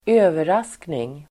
Uttal: [²'ö:veras:kning]